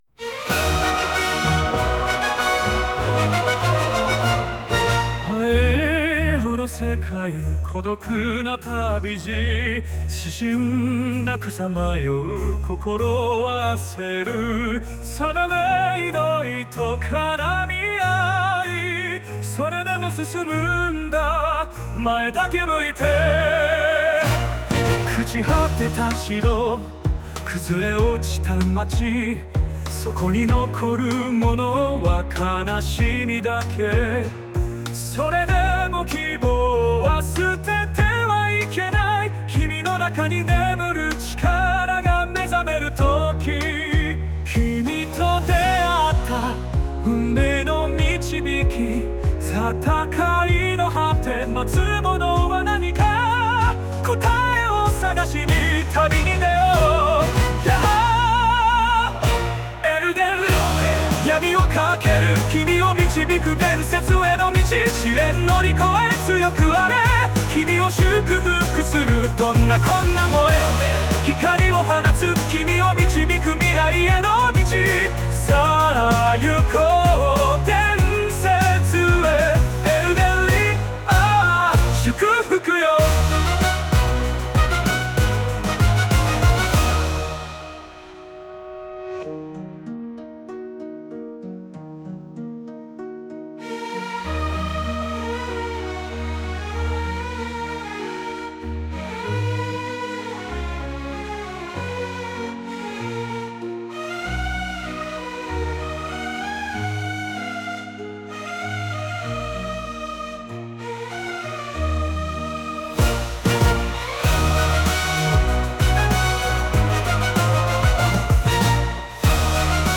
エルデンリングのテーマソングを自分で作詞、AIで作曲したので評価・感想お願いします ［曲名：砕かれた英雄］